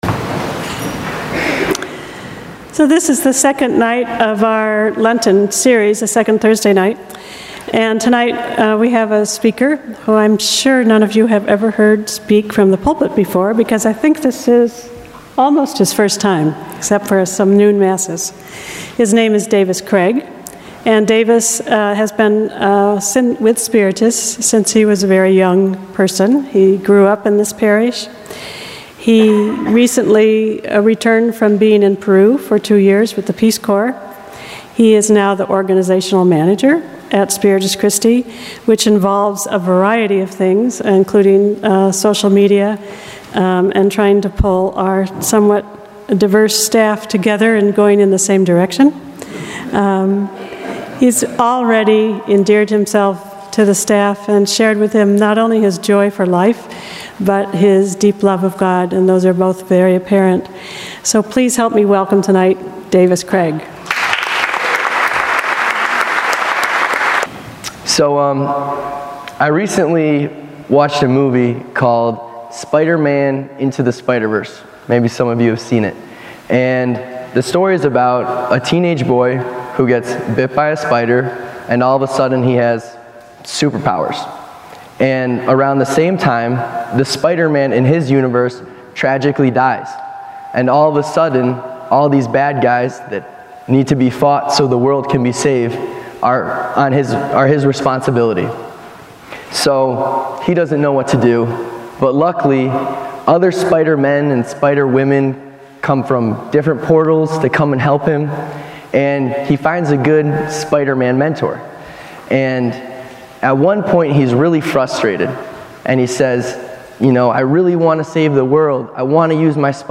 2nd-thursday-of-lent-homily-audio.mp3